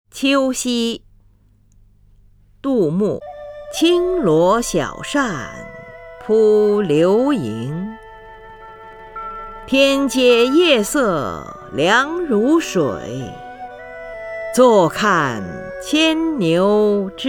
林如朗诵：《秋夕》(（唐）杜牧) （唐）杜牧 名家朗诵欣赏林如 语文PLUS